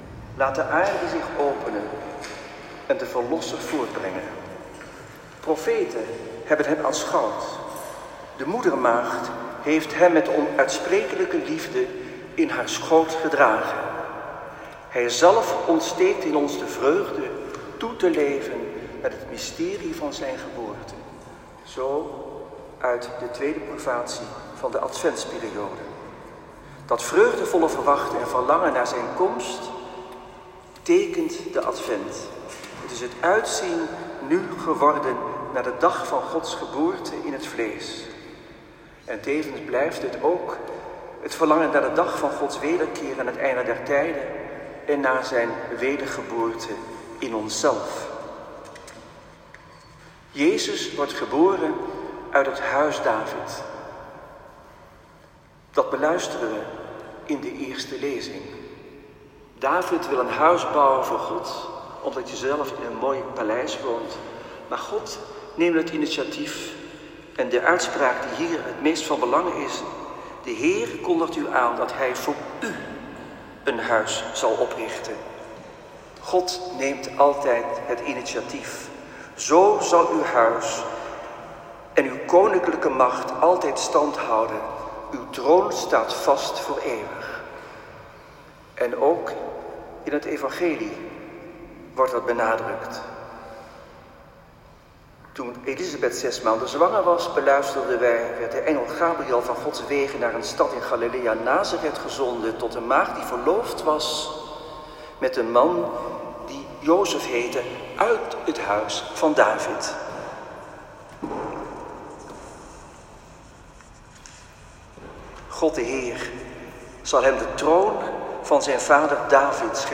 Viering 20 december 2020 Advent IV
Preek-Antoine-Bodar.m4a